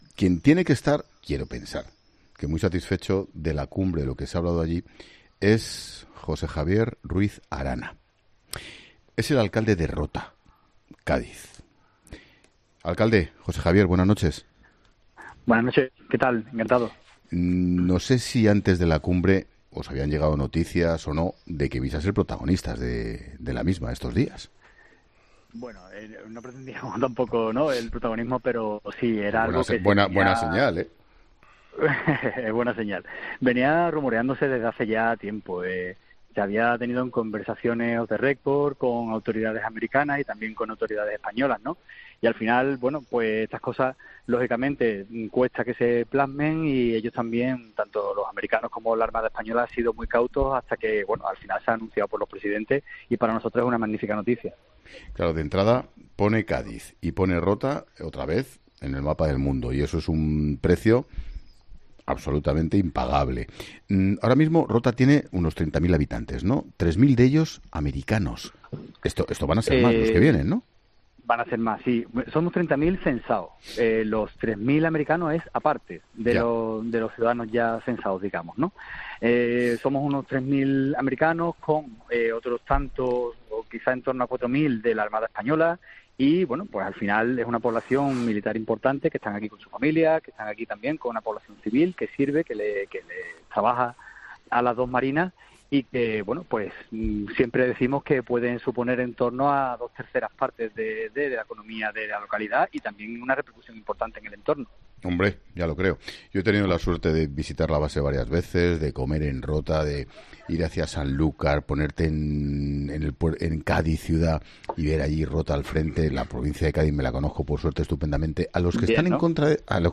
Ruiz Arana ha celebrado en los micrófonos de COPE el anuncio de EEUU indicando que se ampliará de cuatro a seis sus destructores permanentes en la base naval de la ciudad